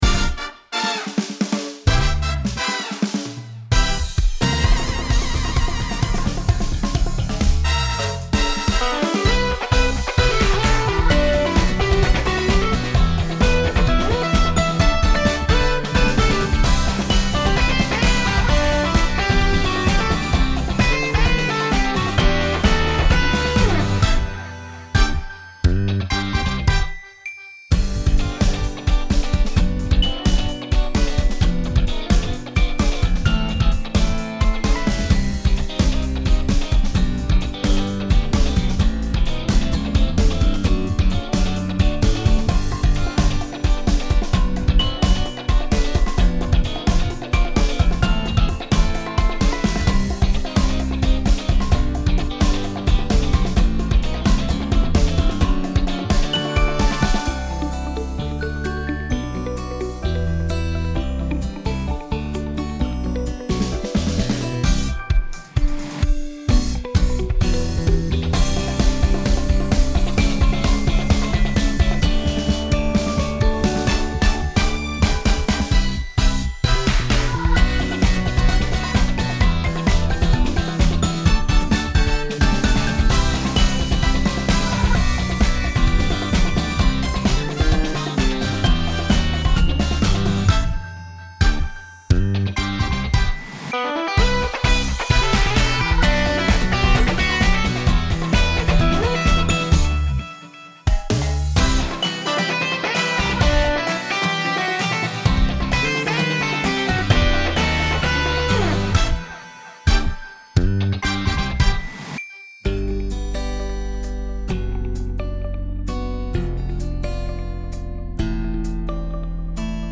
ボーカルとコーラスの両方ともOFFになっております。
エレキギター
爽快
エレキベース
明るい
ポップ
ドラム